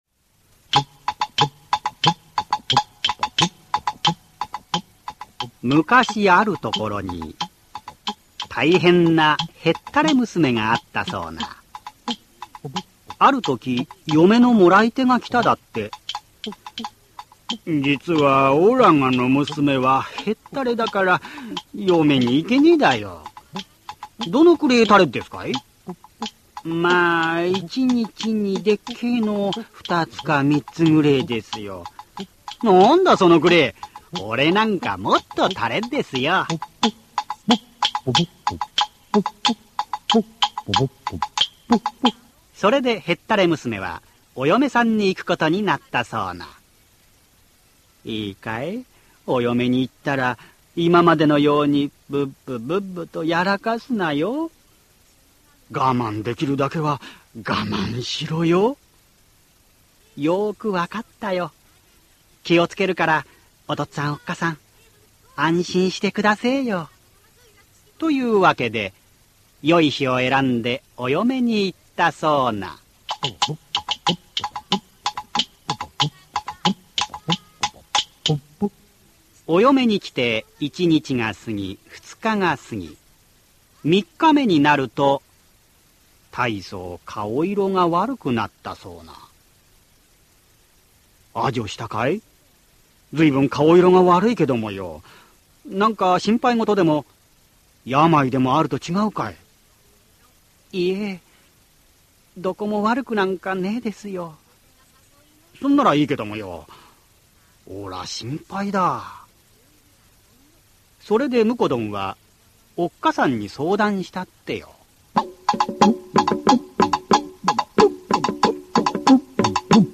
[オーディオブック] へったれよめさん